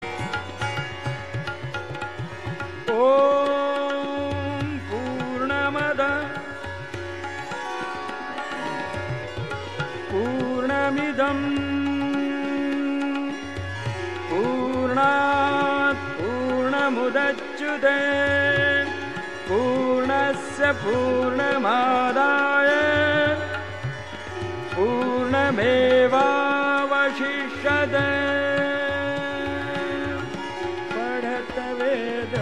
I like Pandit Jasraj’s rendition of that shloka in a classical raga.